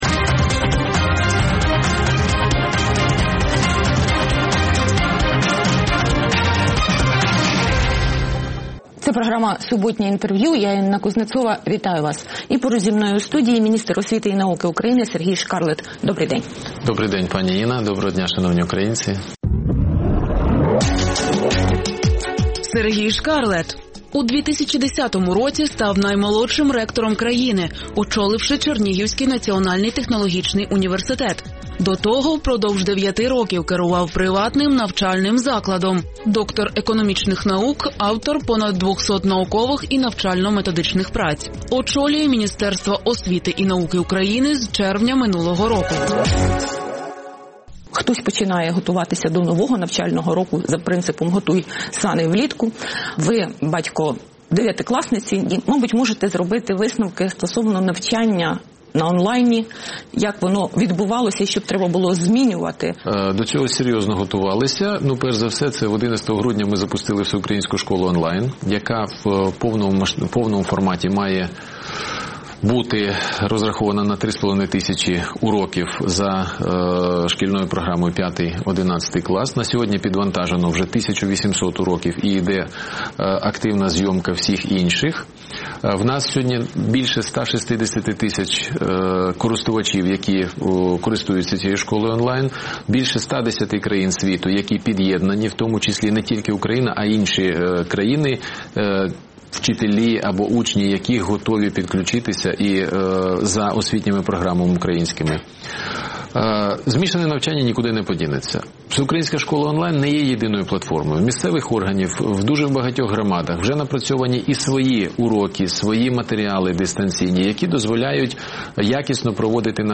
Суботнє інтерв’ю | Сергій Шкарлет, міністр освіти та науки України
Суботнє інтвер’ю - розмова про актуальні проблеми тижня. Гість відповідає, в першу чергу, на запитання друзів Радіо Свобода у Фейсбуці